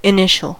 initial: Wikimedia Commons US English Pronunciations
En-us-initial.WAV